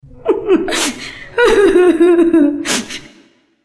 witch_llora_3.wav